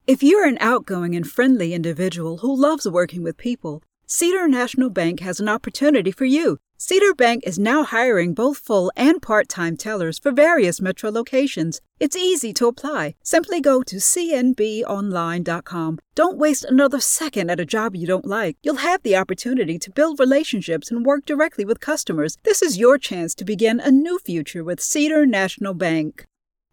Cedar National Bank Commercial
Middle Aged
Female voice talent with a warm, engaging tone, skilled at delivering diverse styles - from friendly commercial ads to authoritative explainer videos.
Experienced in recording from a dedicated home studio, providing quick turnaround times and excellent audio quality.